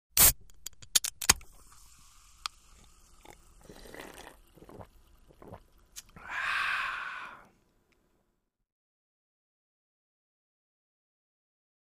Beer Open | Sneak On The Lot
Open Can With Spray, Gulps Swallow And "ahhh".